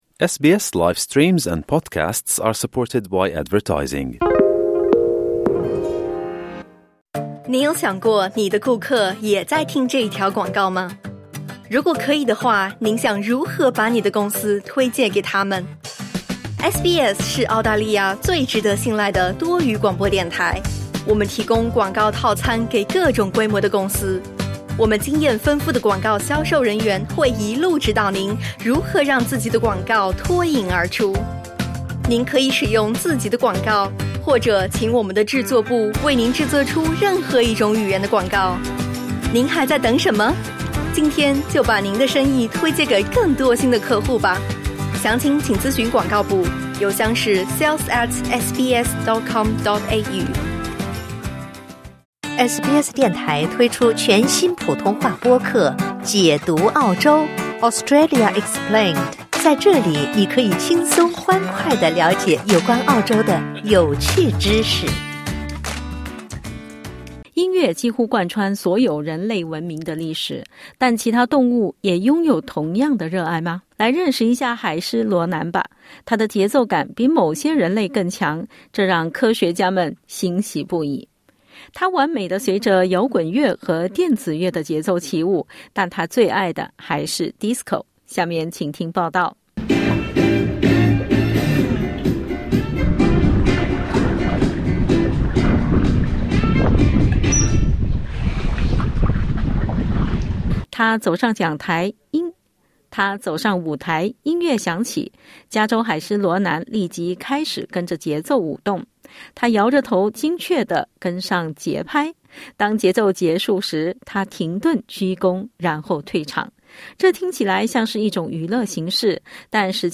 点击音频收听详细采访 欢迎下载应用程序SBS Audio，订阅Mandarin。